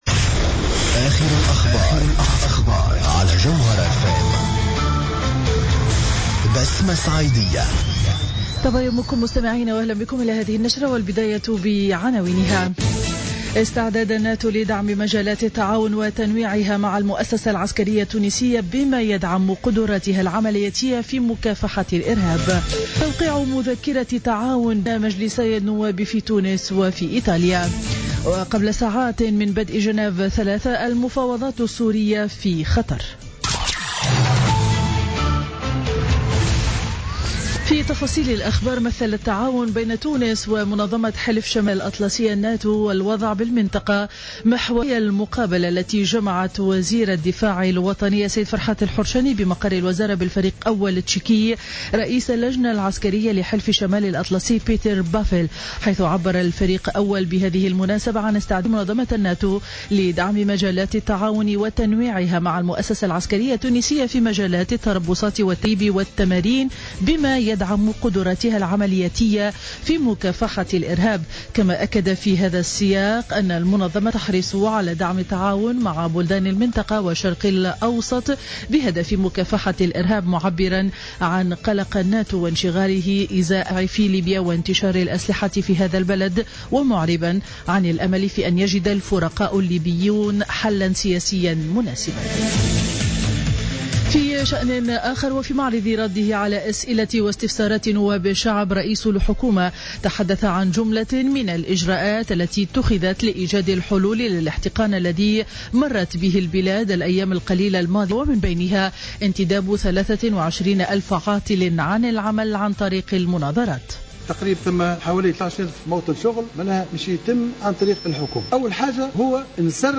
نشرة أخبار السابعة صباحا ليوم الجمعة 29 جانفي 2016